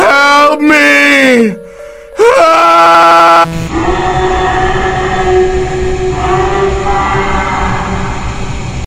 Skin Walker help me Meme Sound Effect
Skin-Walker-Meme-Sound-Effect.mp3